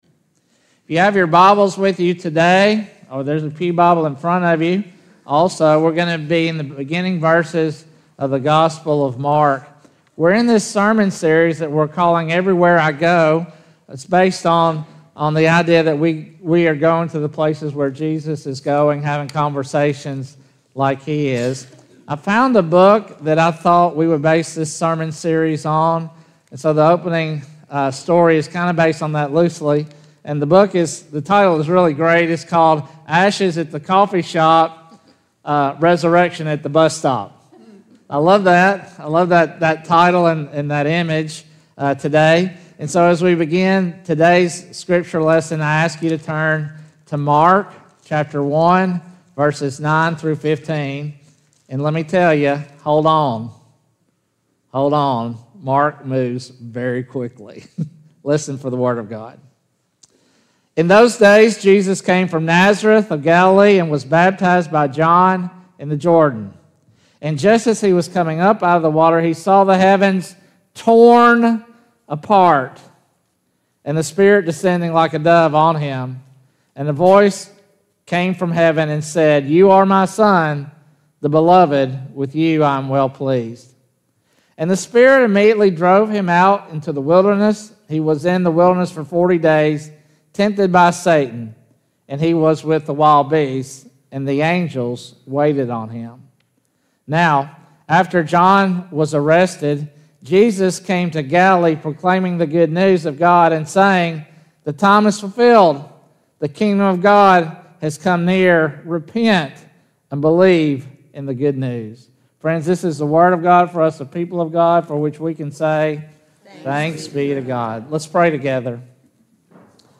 Sermons | First United Methodist Church